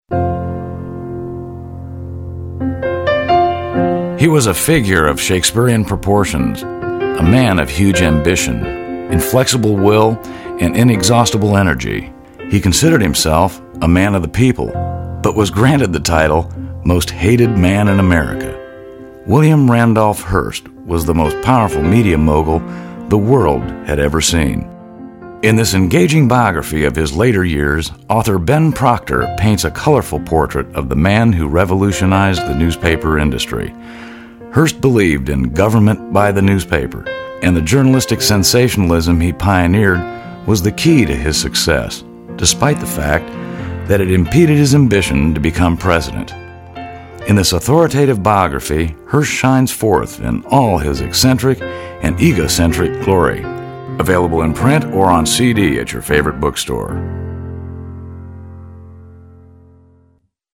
Baritone Voice; Voice Age 40-50; Documentaries, Corporate Narratives, Soft Sell Advertising.
Sprechprobe: eLearning (Muttersprache):